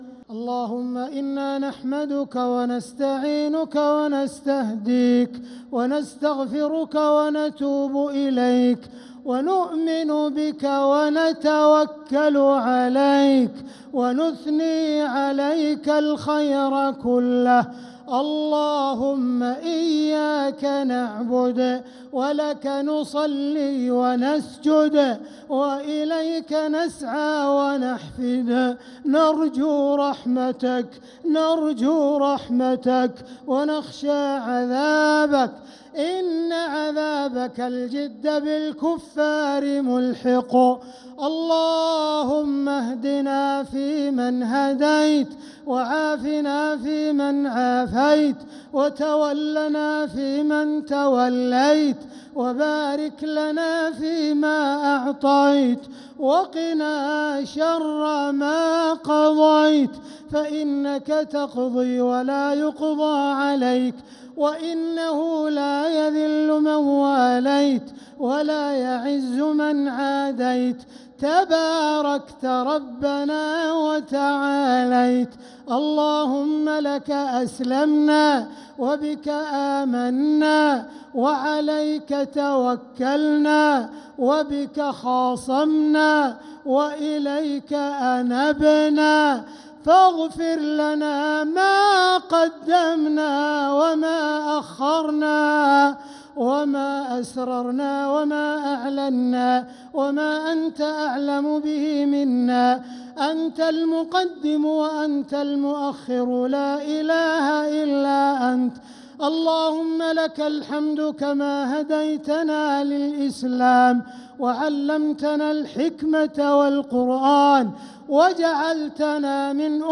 دعاء القنوت ليلة 1 رمضان 1446هـ | Dua 1st night Ramadan 1446H > تراويح الحرم المكي عام 1446 🕋 > التراويح - تلاوات الحرمين